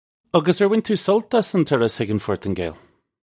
Pronunciation for how to say
Uggus urr win too sult ass un turruss hig un Fortin-gale? (U)
This is an approximate phonetic pronunciation of the phrase.